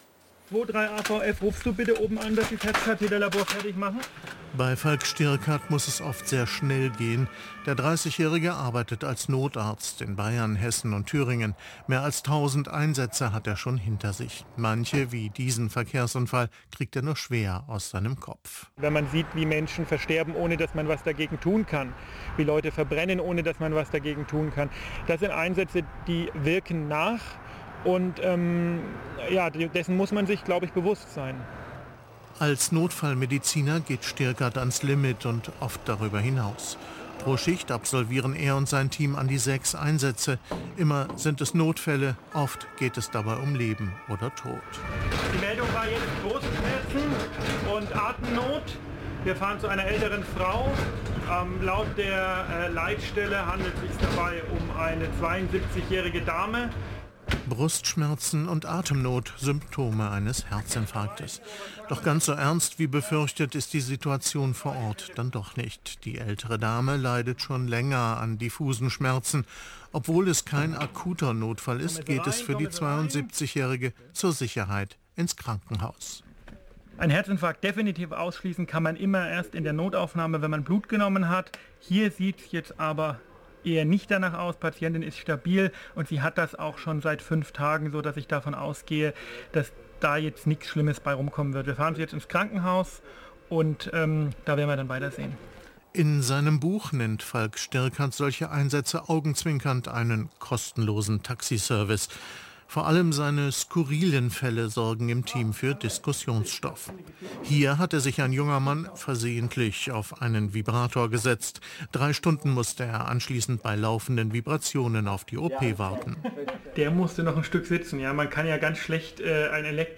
Notarzt-Reportage
(Audiospur des TV-Beitrags aus Brisant, ARD/Das Erste vom
Notarzt-Reportage.mp3